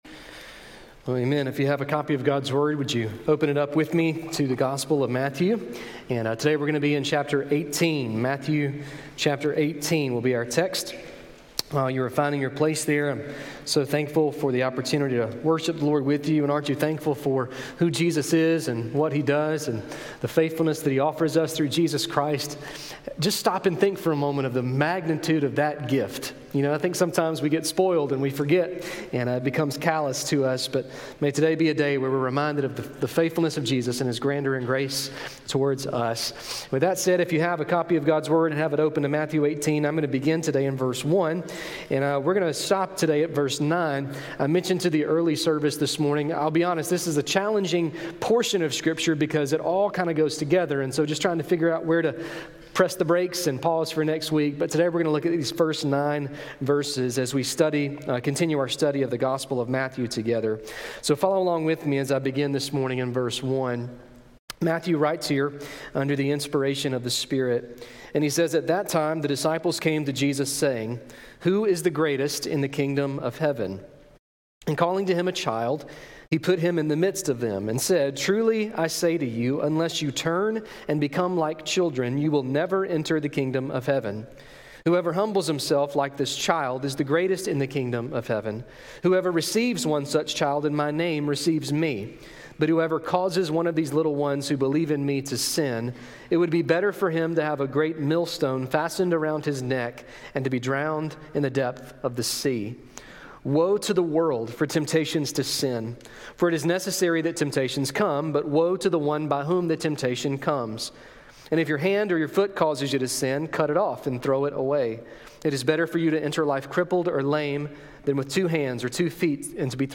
A message from the series "Your Kingdom Come."